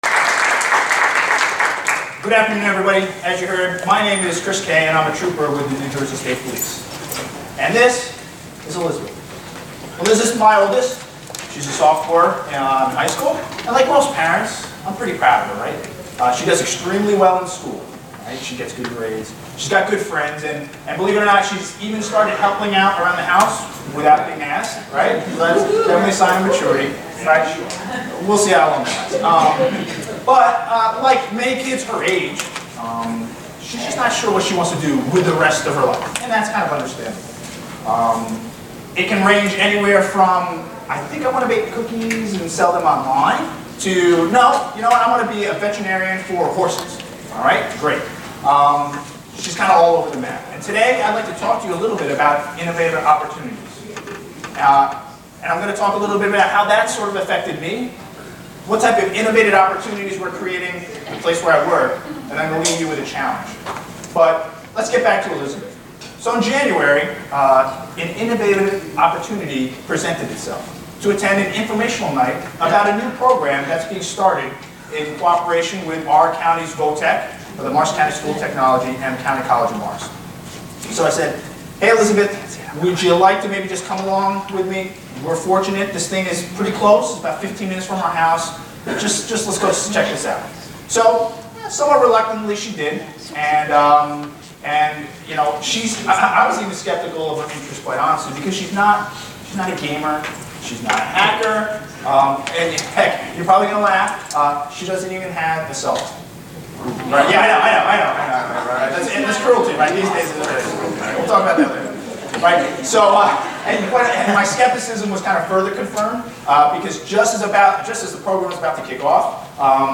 Keynote Address #2